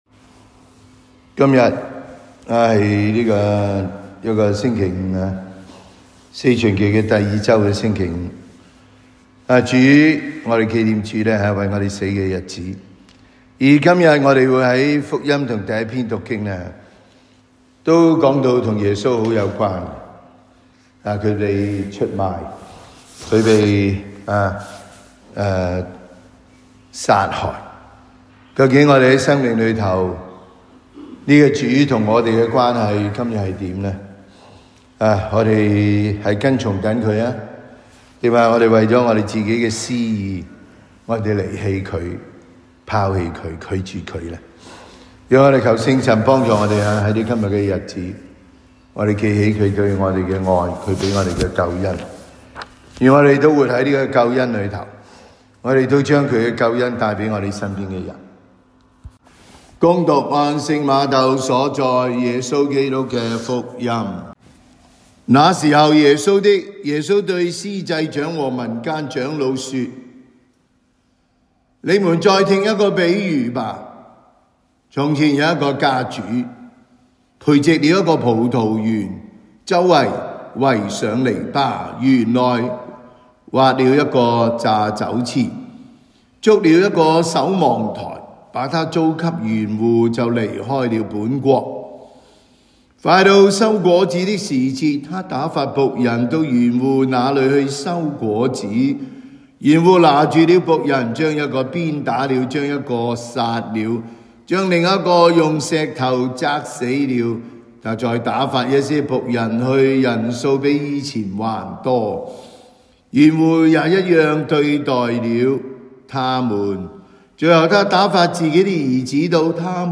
感恩祭講道